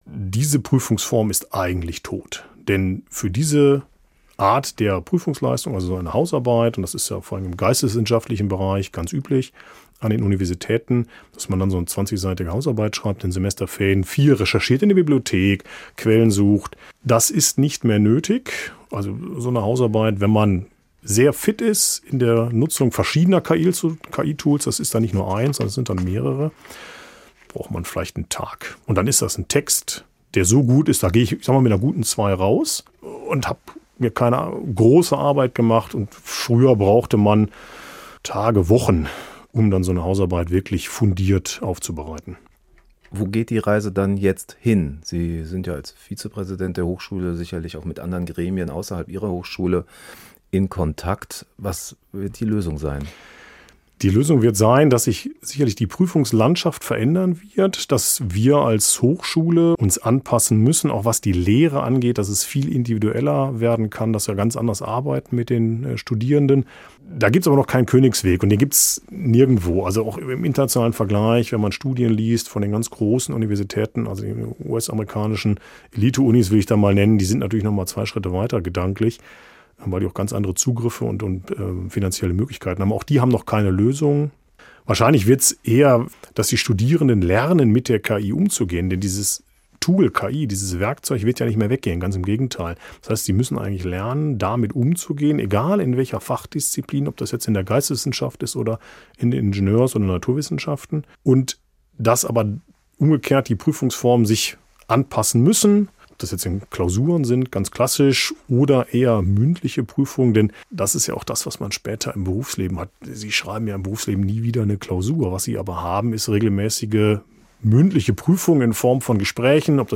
Interview: KI und Bildung – Zwischen Innovation und Betrug - SWR Aktuell